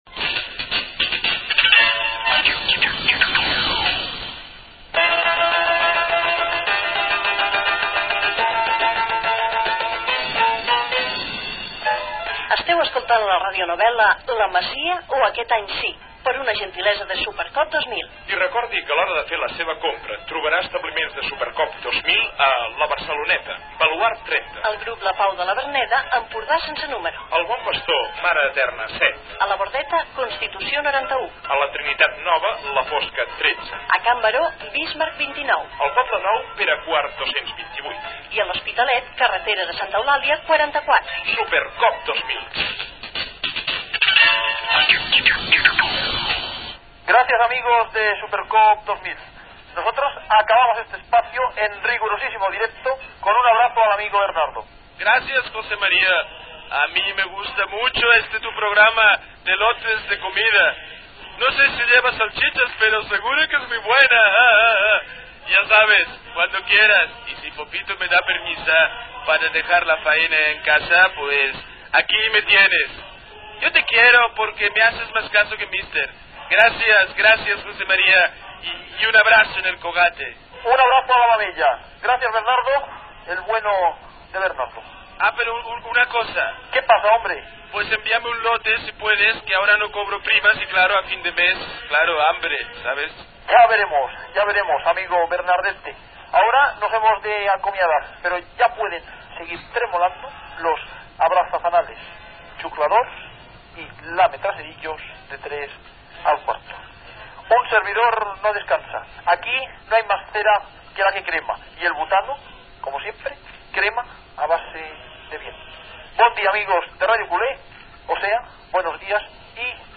La radionovel·la de la Cadena 13 "La masia o aquest any sí".
Publicitat, identificació del programa.
Ficció